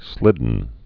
(slĭdn)